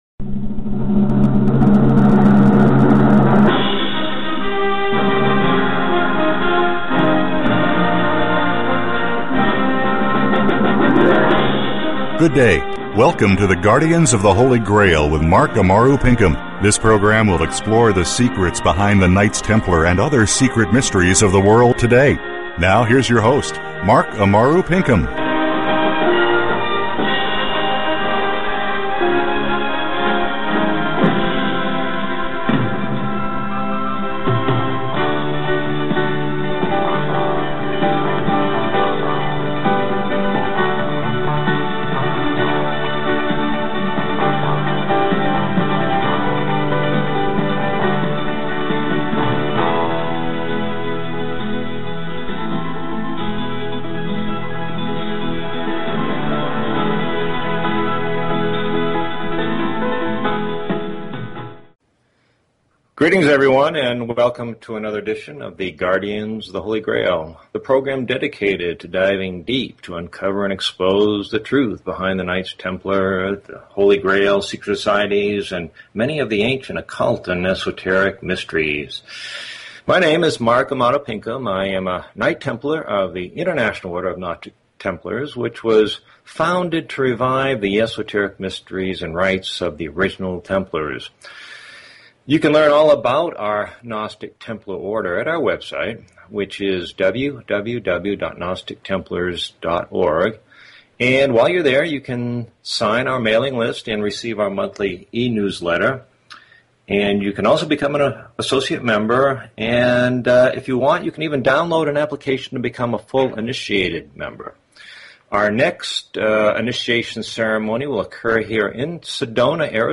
The Guardians of The Holy Grail Please consider subscribing to this talk show.